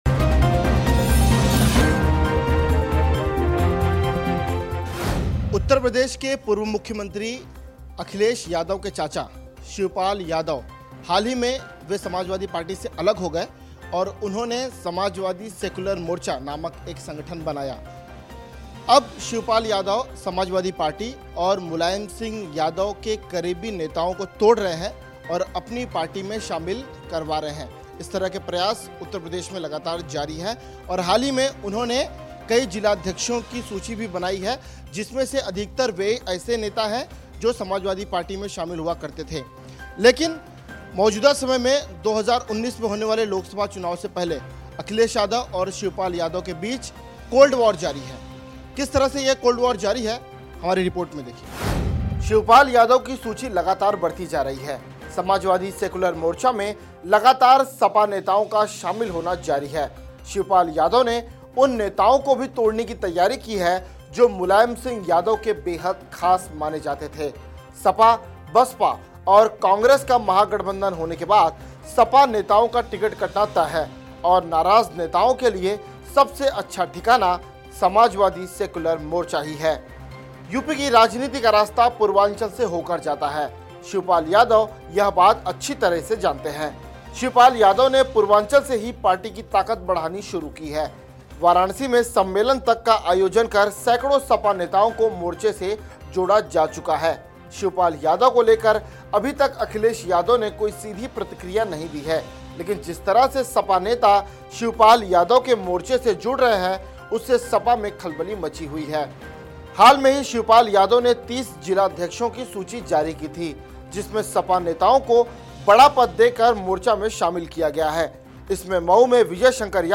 न्यूज़ रिपोर्ट - News Report Hindi / शिवपाल का अखिलेश को झटका, सपा में मुलायम सिंह यादव की बढ़ी मुश्किलें !